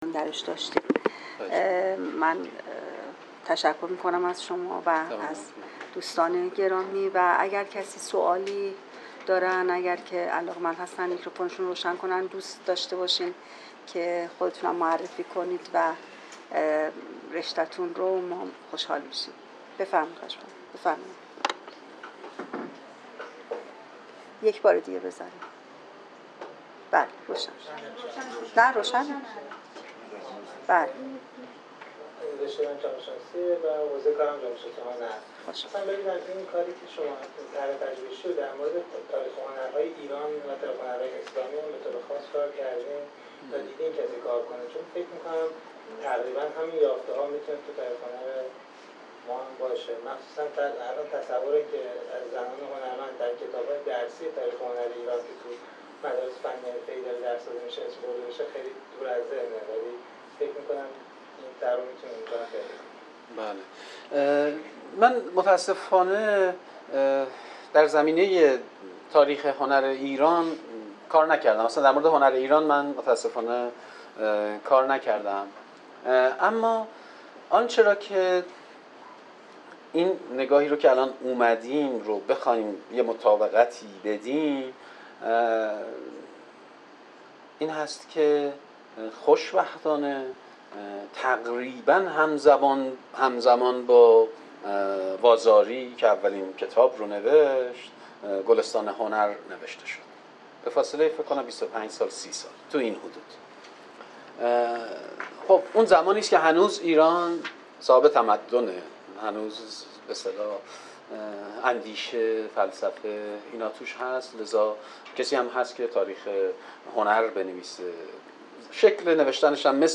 سخنرانی علمی: زنان و تجدید نظر در تاریخ هنر
سالن اندیشه